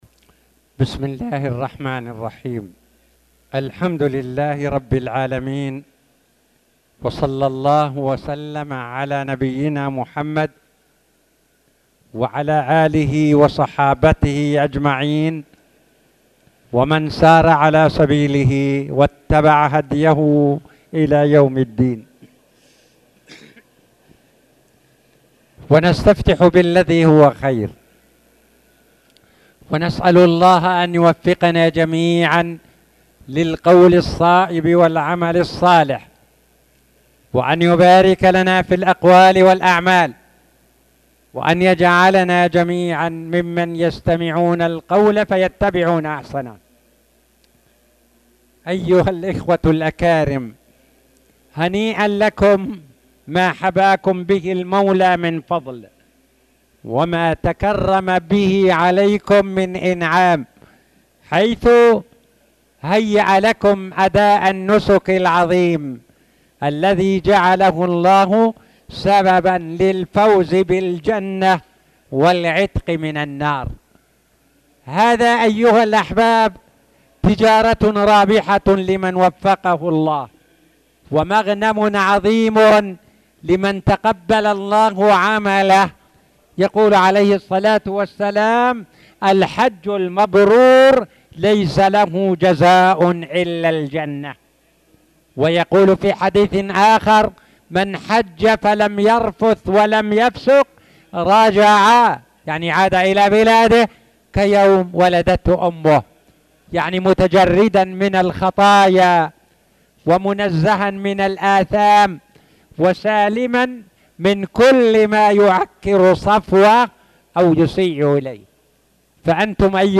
تاريخ النشر ١٦ ذو القعدة ١٤٣٧ هـ المكان: المسجد الحرام الشيخ